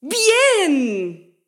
Mujer contenta: ¡Bien!
contento
exclamación
mujer
Sonidos: Voz humana